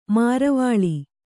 ♪ maravāḷ